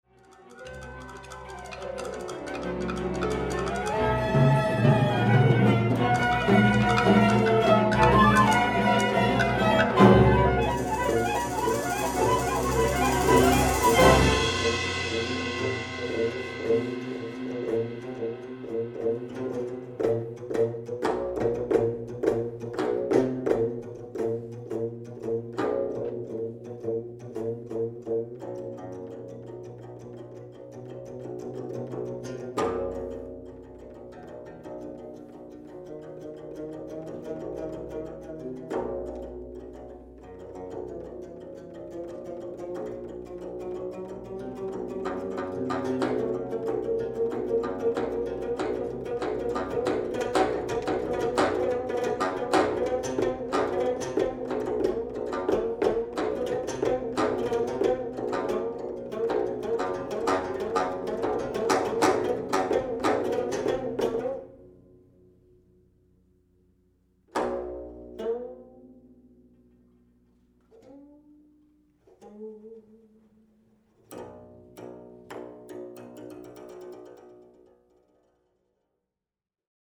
geomungo concerto